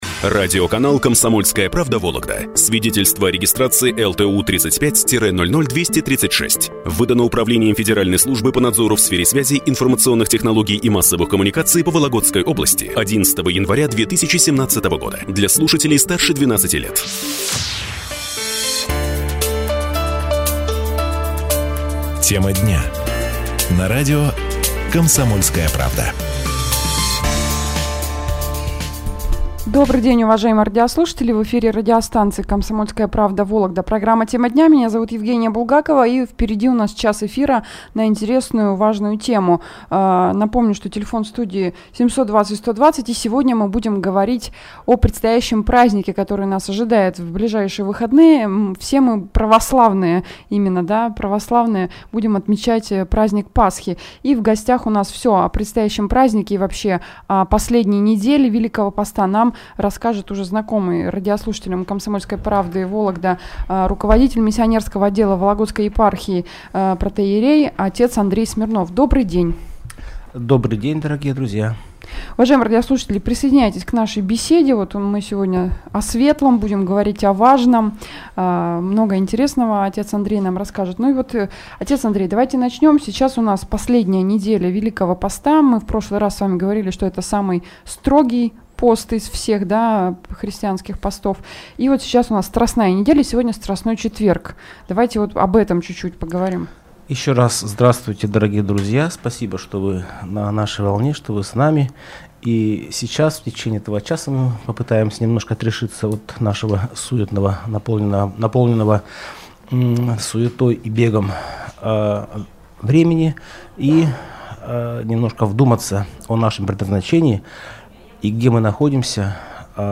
В ходе прямого эфира состоялся разговор о смысле, традициях и особенностях дней Страстной седмицы и их особой важности в преддверии праздника Святой П...